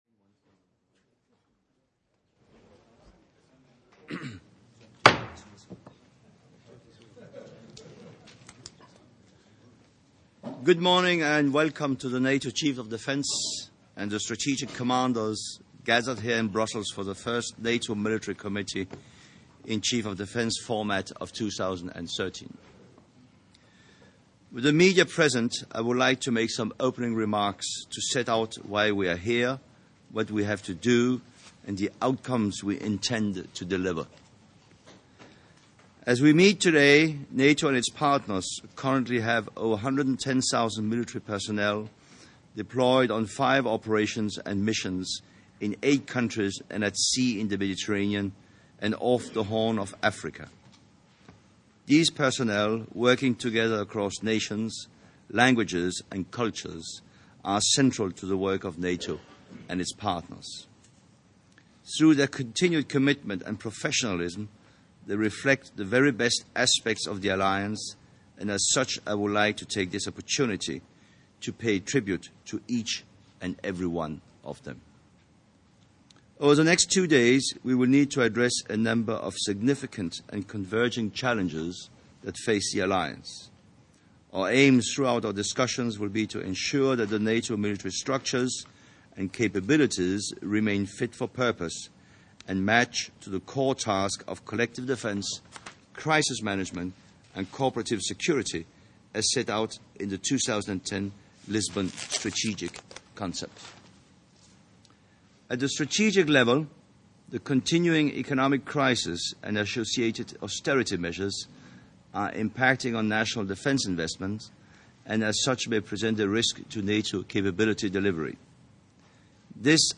Opening remarks
by the Chairman of the Military Committee, General Knud Bartels, at 168th meeting of the Military Committee in Chiefs of Staff session